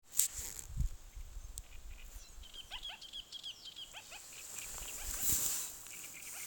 Birds -> Rails ->
Little Crake, Zapornia parva
Otrs pie purva malas atsaucās/nodziedāja un pēc tam ik pa laikam izdeva saucienus. Vienu tādu ierakstīju.